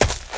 STEPS Leaves, Run 07, Heavy Stomp.wav